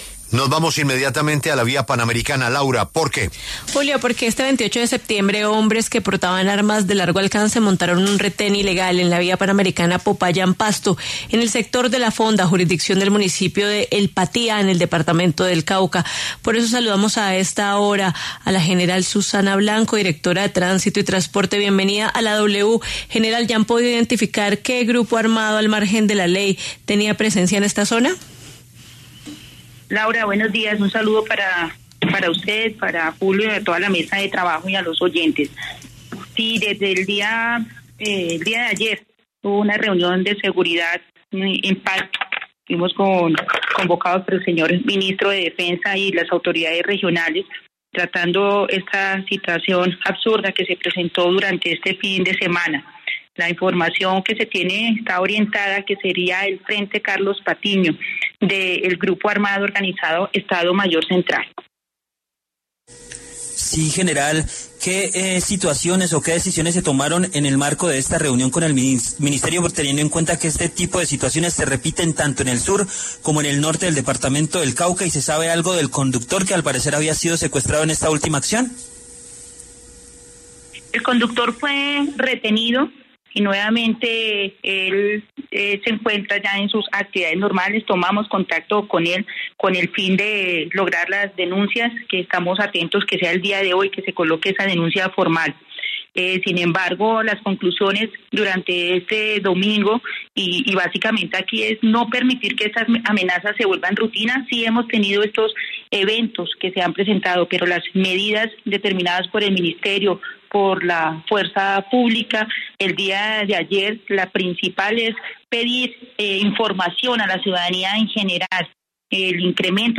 Al respecto, la general Susana Blanco, directora del Departamento de Tránsito y Transporte, reveló en La W que los responsables de estos hechos fueron el Frente Carlos Patiño del Estado Mayor Central.